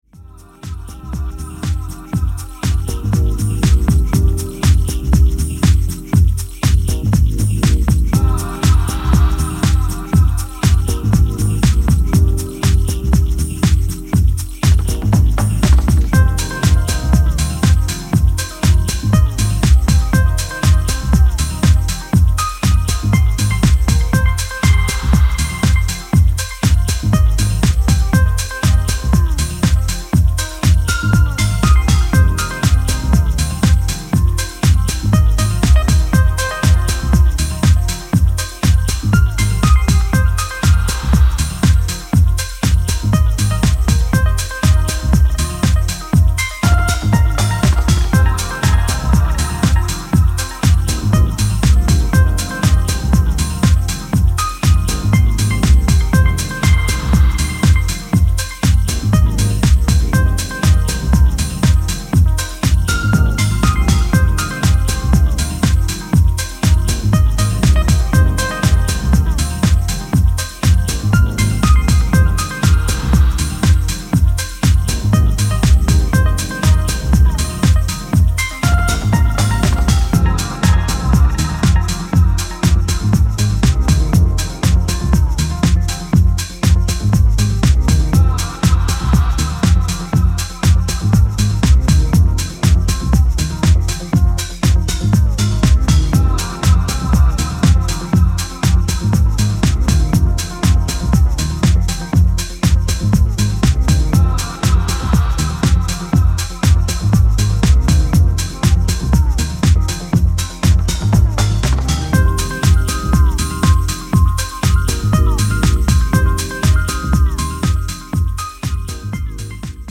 breezy house grooves